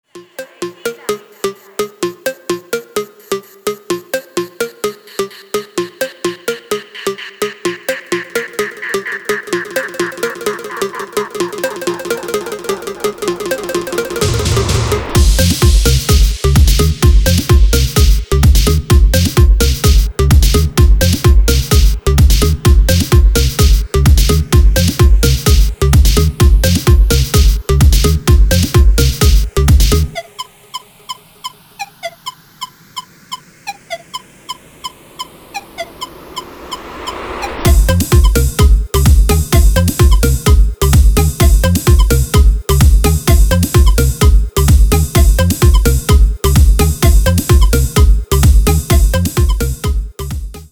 • Качество: 320, Stereo
громкие
dance
Electronic
EDM
электронная музыка
без слов
club
house